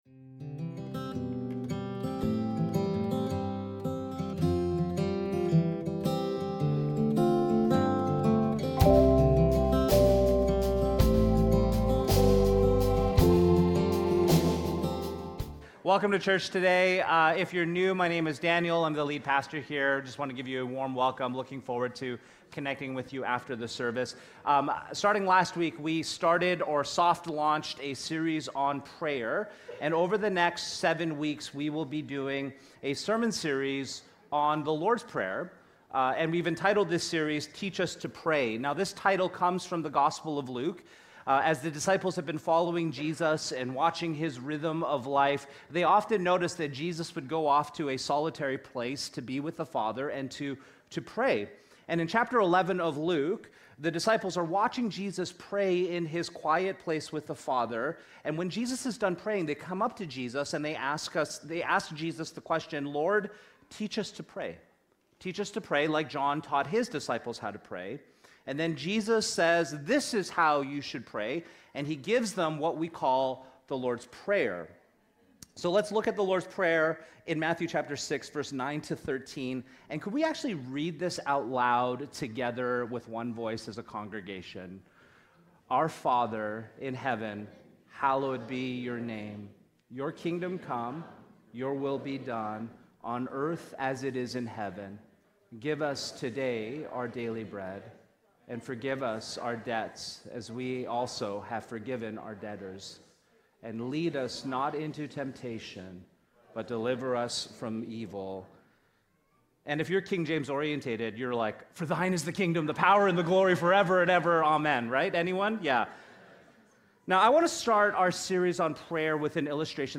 co-preach and hone our focus on two themes, the transcendence of God and the immanence of God.